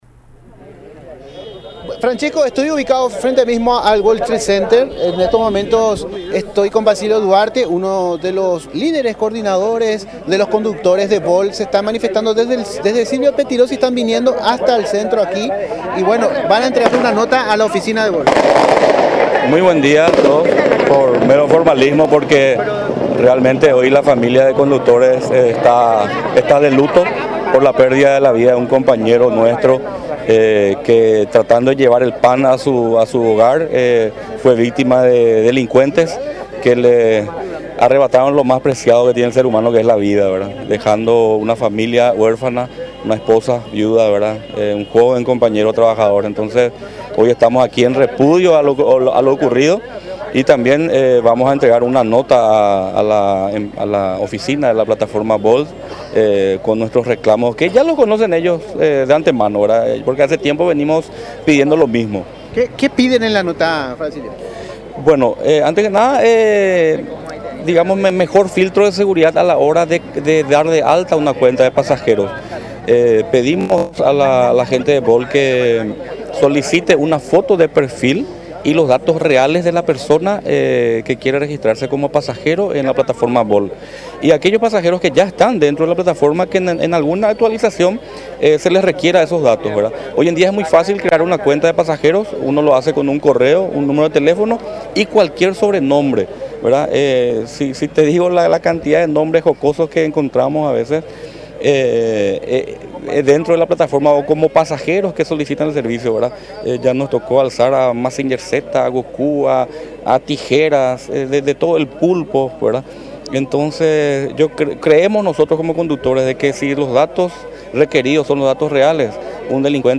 “Pedimos mejor filtro de seguridad a la hora de dar de alta a la cuenta de un pasajero”, mencionó uno de los conductores manifestantes en entrevista